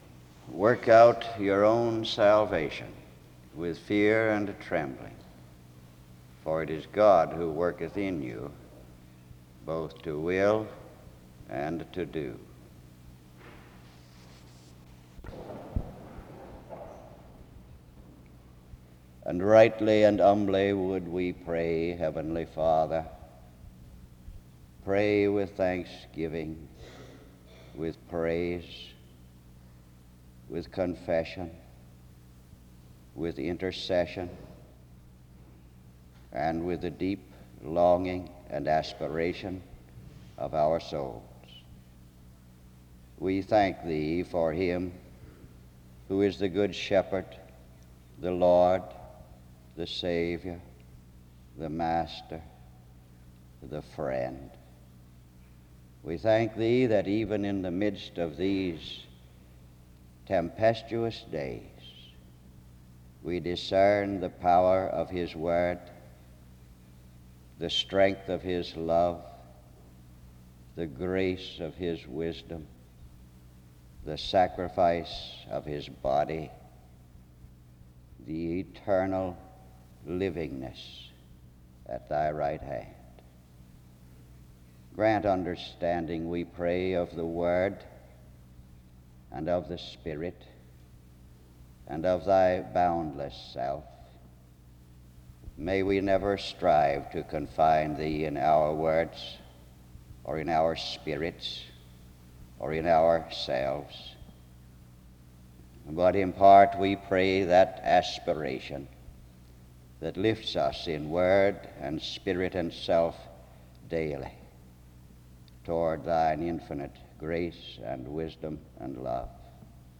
The service begins with the reading of Philippians 2:12b-13 (00:00-00:18), prayer (00:19-03:42), and announcements (03:43-09:26).
He proceeds to read 2 Corinthians 2:14-17 and Hebrews 13:15-16 from the KJV (09:27-11:26), and then the same passages again from the Amplified New Testament (11:27-13:38).